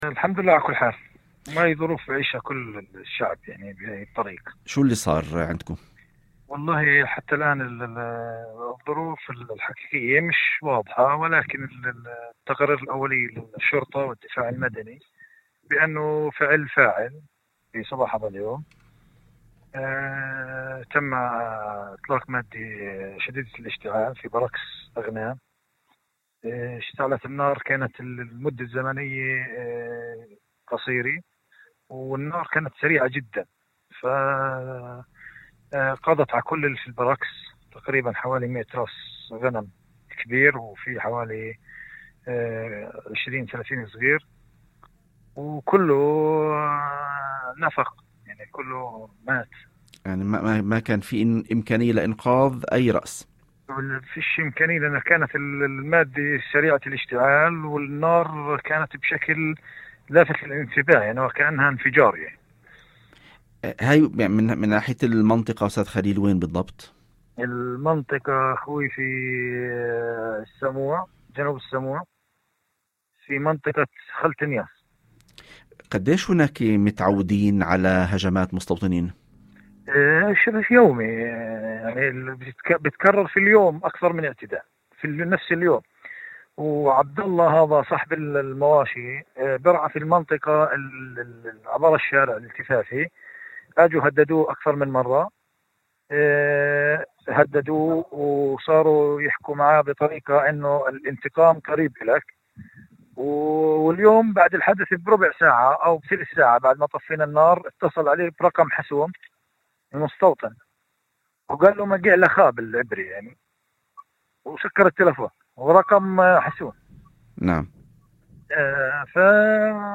في حديثه لإذاعة الشمس عبر برنامج يوم جديد